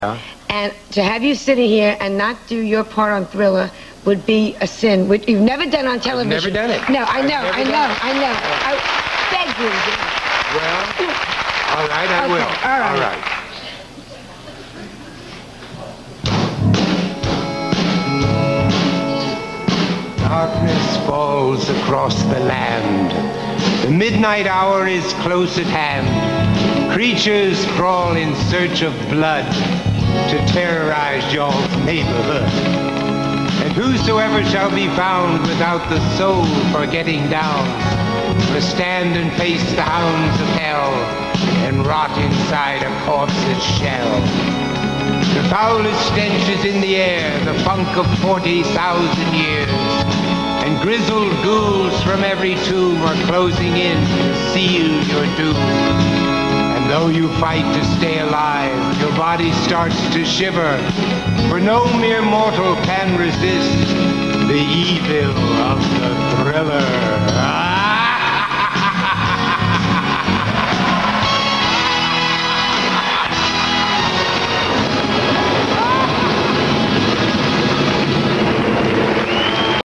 What a voice 👏👏 Vincent Price on The Late Show Starring Joan Rivers, 1987.